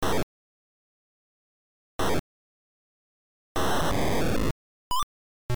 This is the shooting sound for the game Tombstone City.
Tombstone_City_Shooting_Aliens.mp3